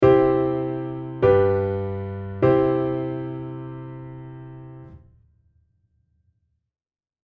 という緊張感の走る音ということになります。
子供の頃、よくこの音を聴くとお辞儀をしていましたね！